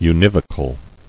[u·niv·o·cal]